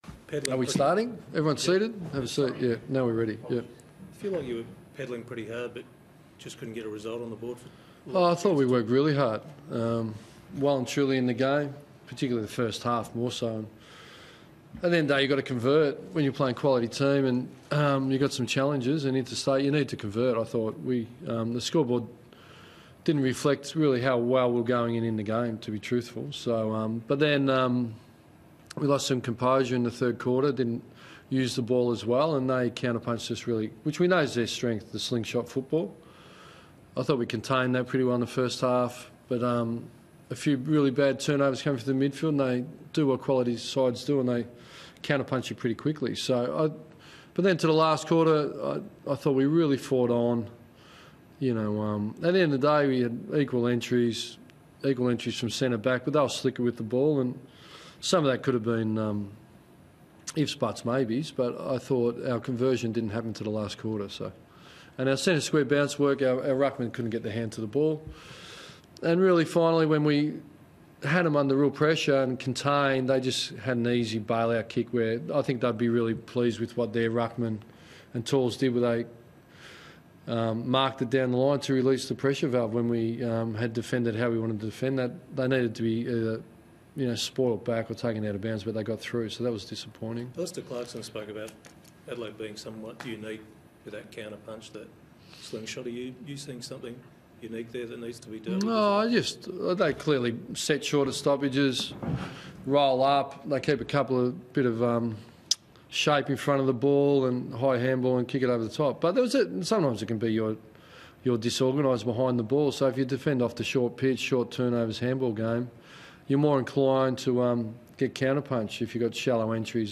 Ross Lyon spoke to the media following the loss against Adelaide